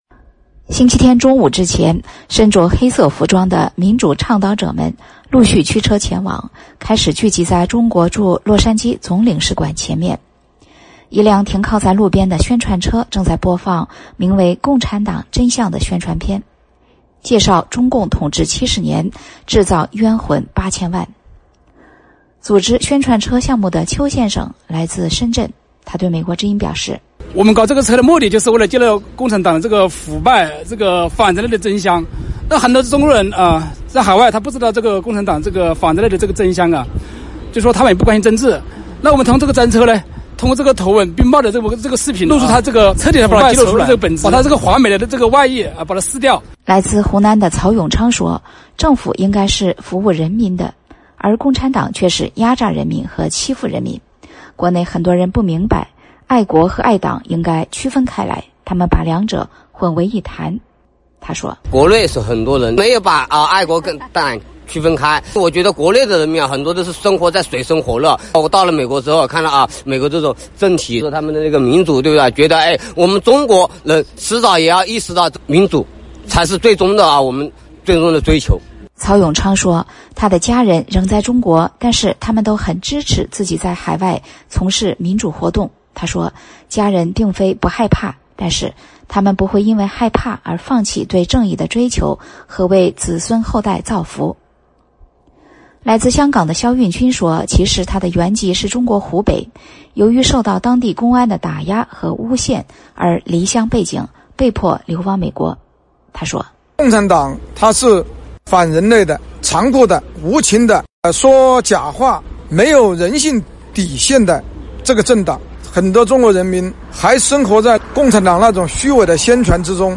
集会者2019年9月29日中午在中国驻洛杉矶总领事馆门前集会抗议独裁
他们身着黑色服装，手举“建立民主中国”、“国殇”和“还我房子、还我家园”等标语，呼喊“自由万岁、争取宗教自由、争取言论自由”等口号，抨击独裁，倡导建立民主自由中国，同时呼吁支持香港。